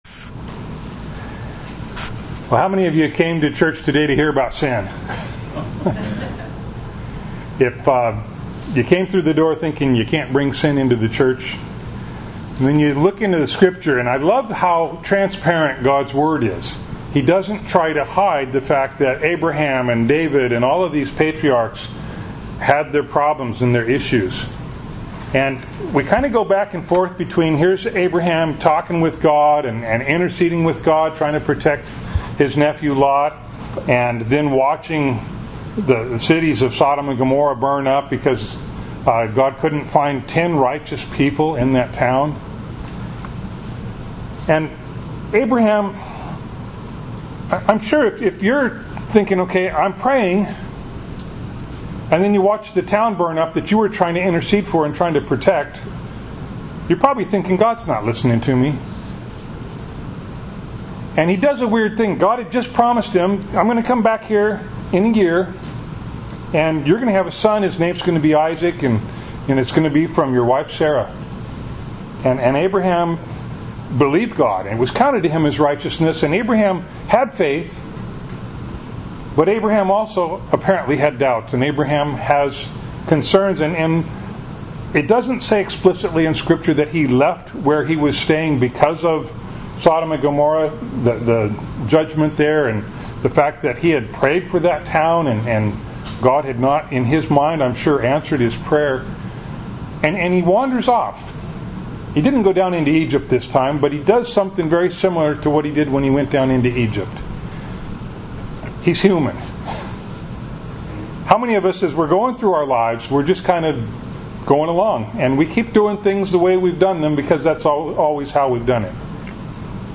Bible Text: Genesis 20:1-18 | Preacher: